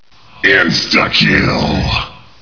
SOUNDS: Add revised announcer sounds
insta_kill.wav